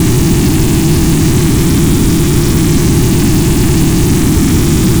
spaceEngineLarge_004.ogg